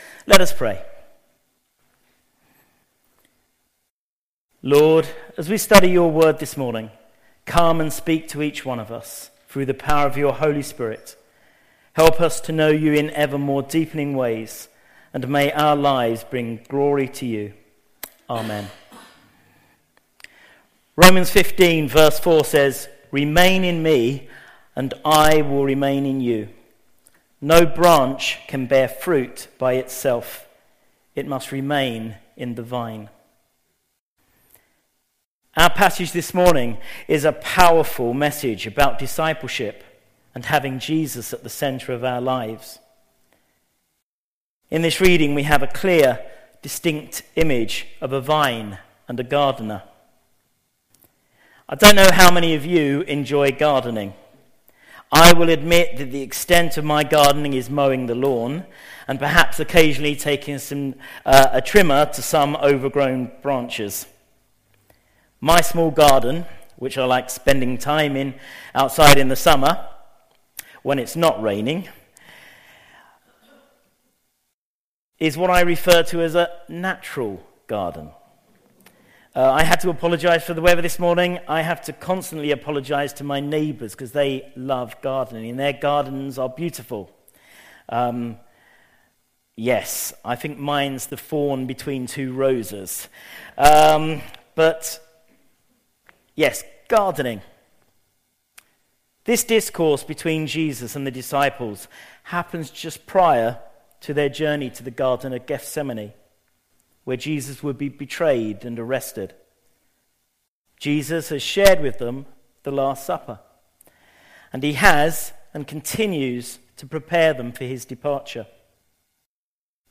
There is an audio version of the sermon also available.
Passage: John 15:1-8, 1 John 4:7-21 Service Type: Sunday Morning
04-28-sermon.mp3